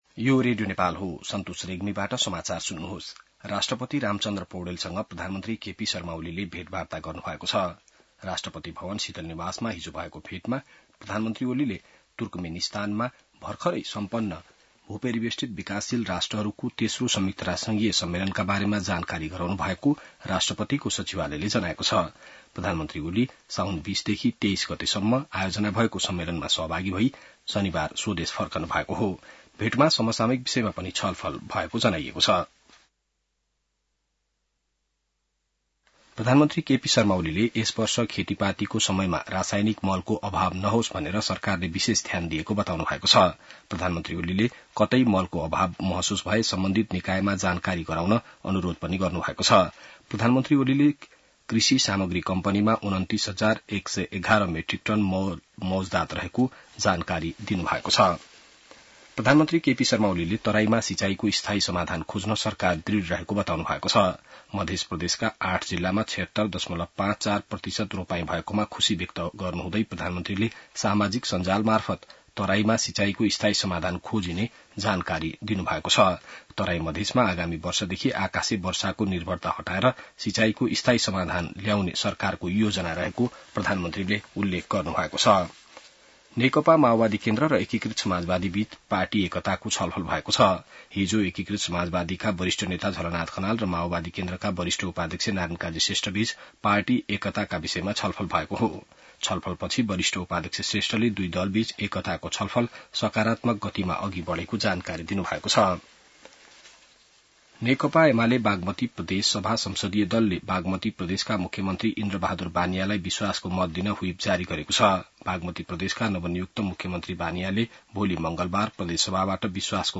बिहान ६ बजेको नेपाली समाचार : २६ साउन , २०८२